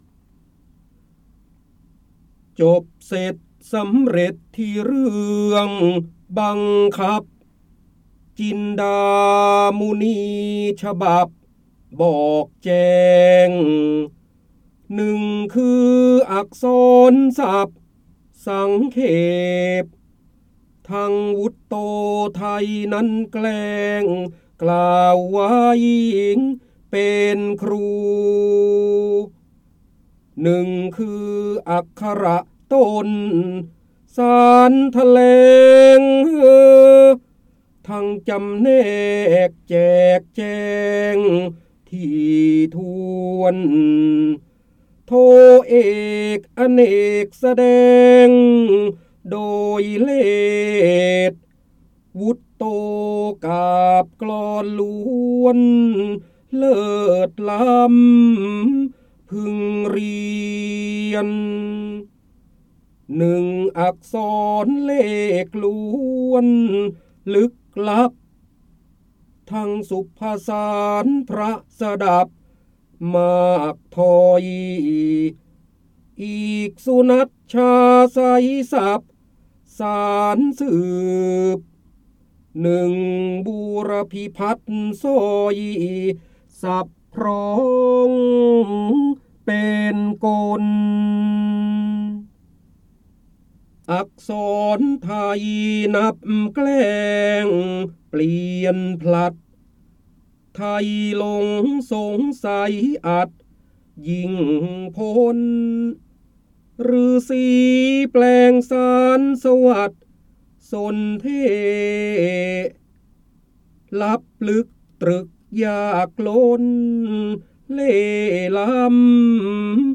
เสียงบรรยายจากหนังสือ จินดามณี (พระโหราธิบดี) จบเสร็จสำเร็จธิเรื่อง บังคับ
คำสำคัญ : ร้อยแก้ว, ร้อยกรอง, พระเจ้าบรมโกศ, จินดามณี, การอ่านออกเสียง, พระโหราธิบดี